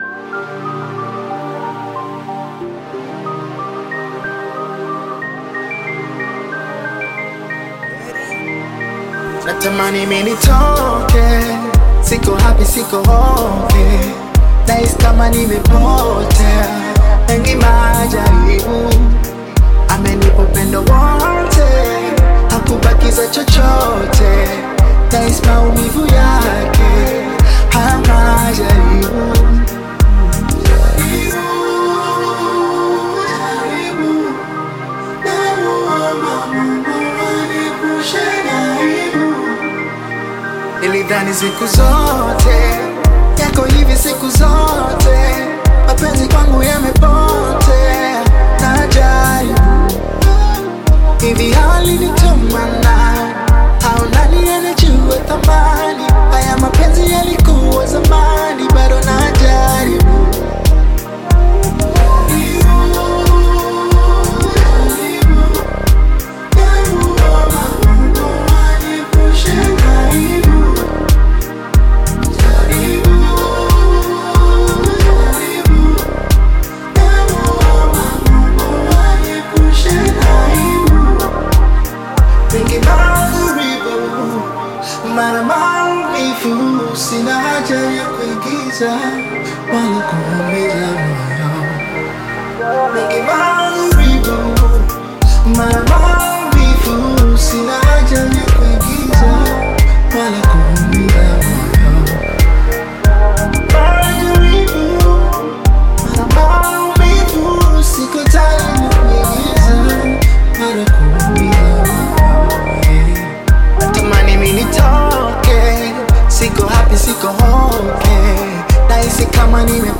Bongo Flava music track
Tanzanian Bongo Flava artist and singer